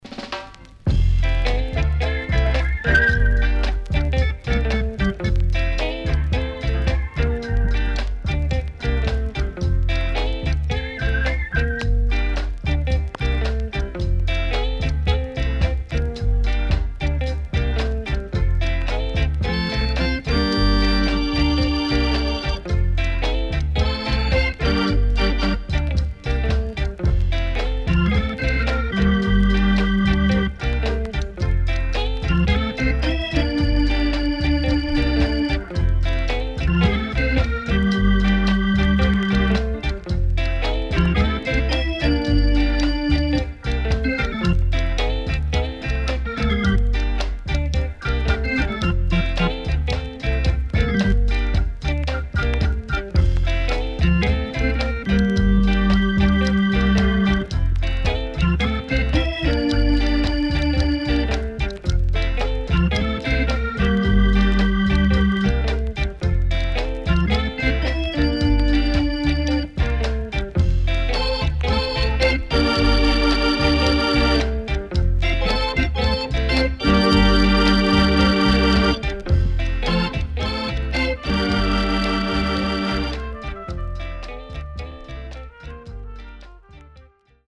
CONDITION SIDE A:VG(OK)
いろいろなアーティストにCoverされている名曲 Good Vocal & Inst
SIDE A:所々チリノイズ、プチノイズ入ります。